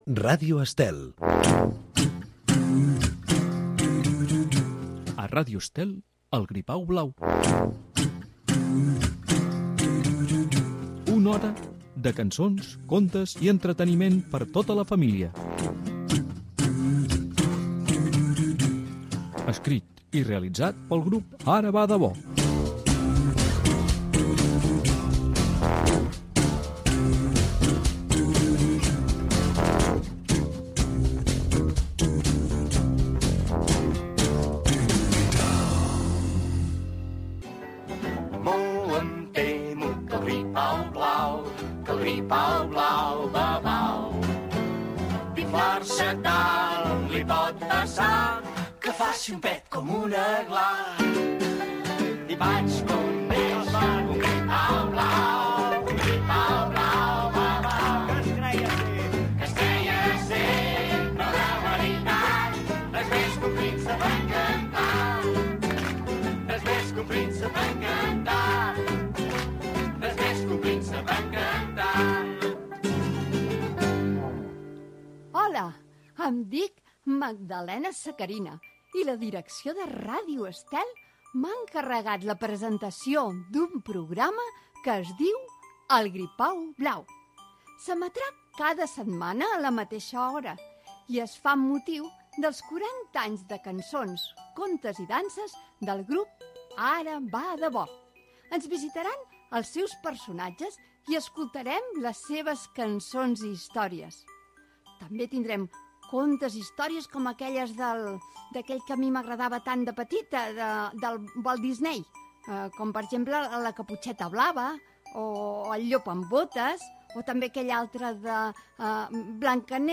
Infantil-juvenil
Fragment extret del web de Ràdio Estel.